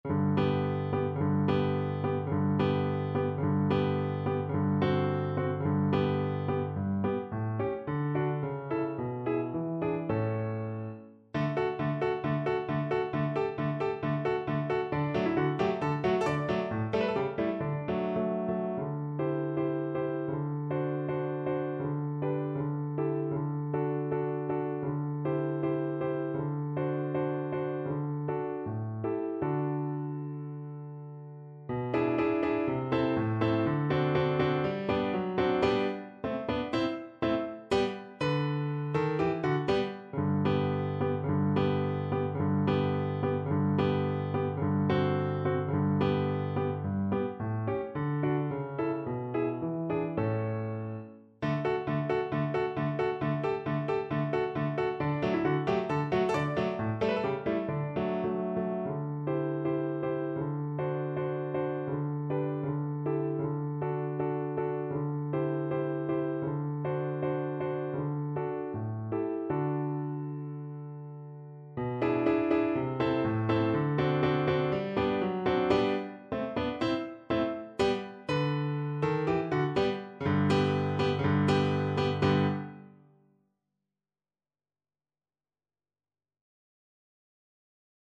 Moniuszko: Krakowiaczek “Wesół i szczęśliwy” (na wiolonczelę i fortepian)
Symulacja akompaniamentu